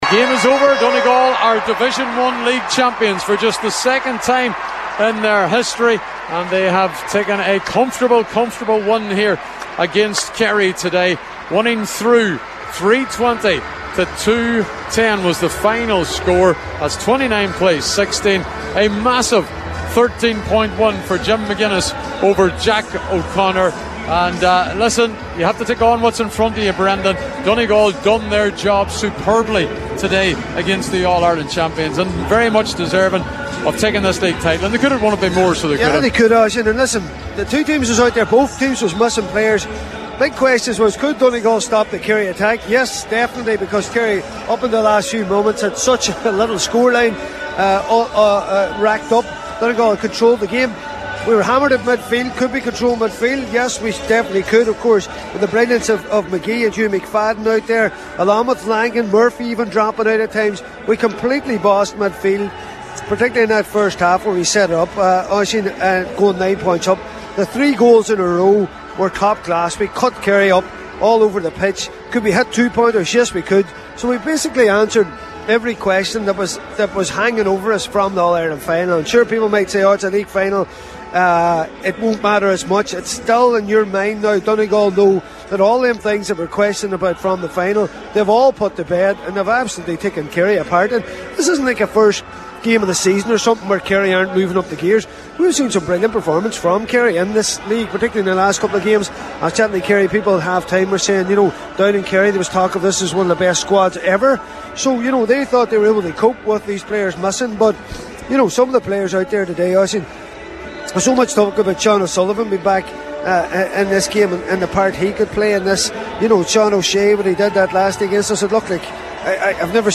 Donegal run riot against Kerry in Division 1 League Final – Post-Match Reaction